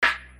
Snare 1.mp3